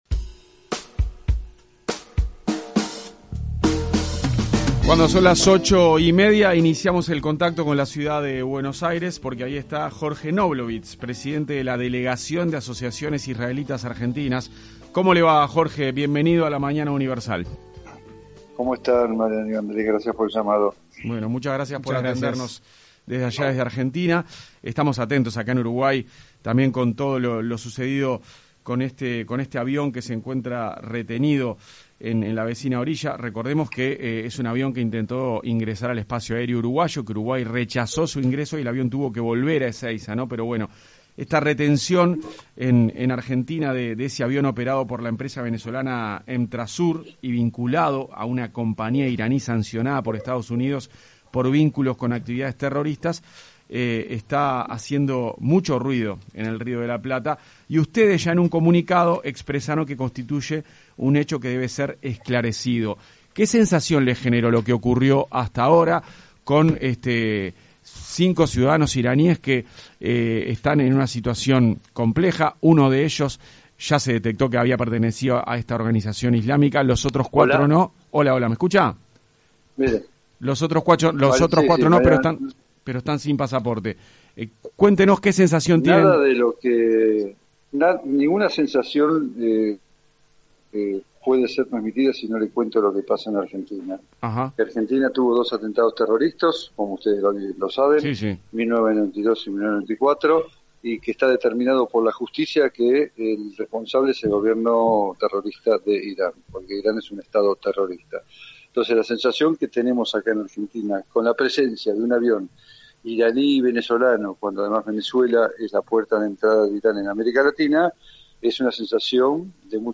Entrevista-completa-14-de-6.mp3